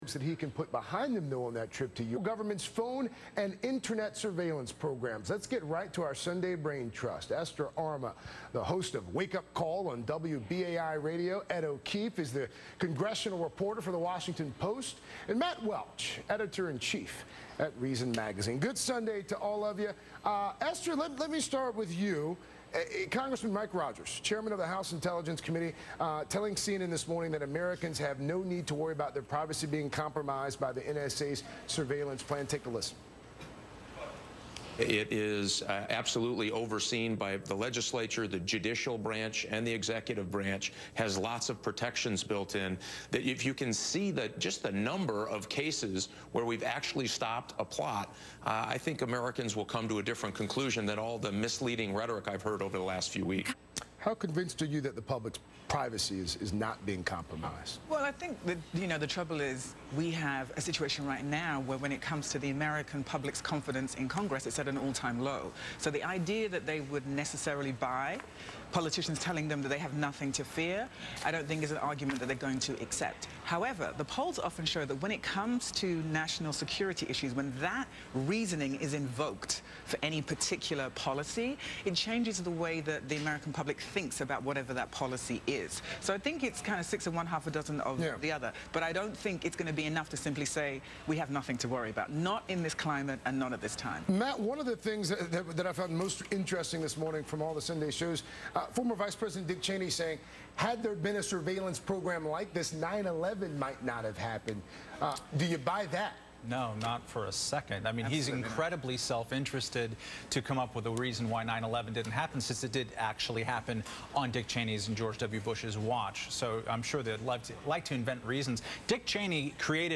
appeared on MSNBC's Sunday Live to discuss officials' claims that NSA programs prevented terrorist attacks.